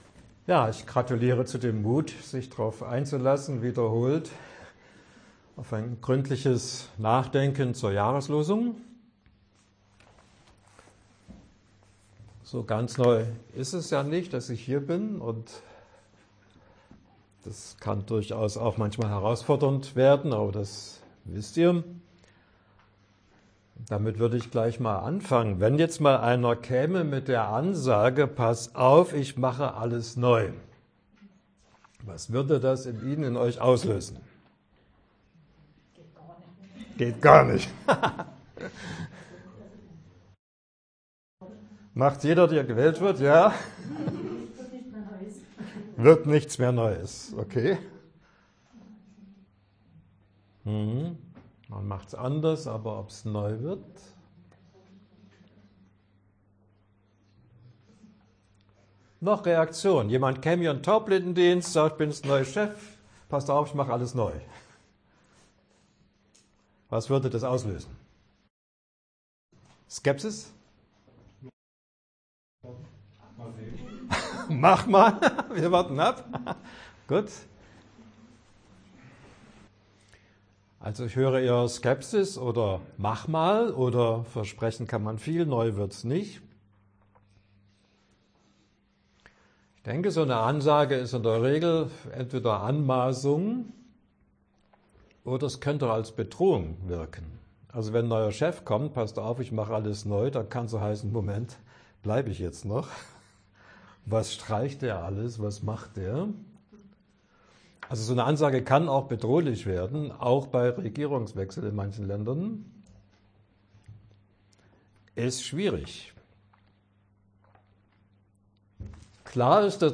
Audiodateien Vorträge